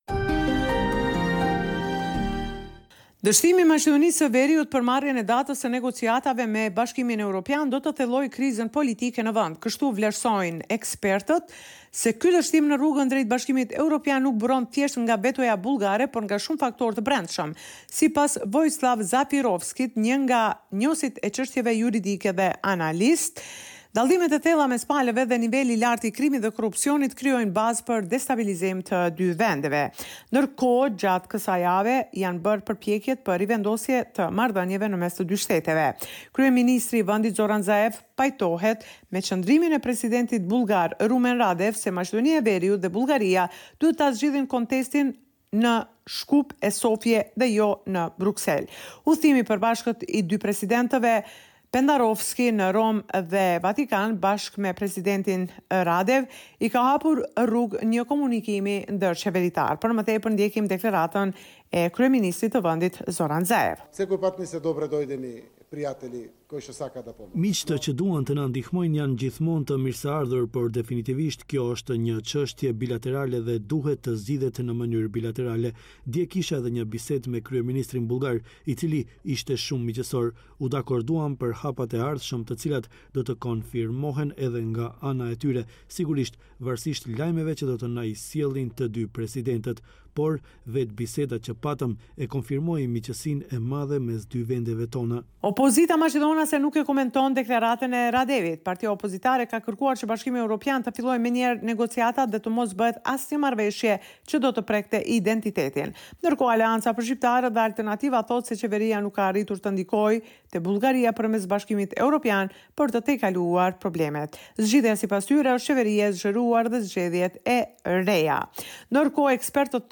Raporti me te rejat me te fundit nga Maqedonia e Veriut.